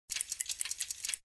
CHQ_FACT_switch_depressed.ogg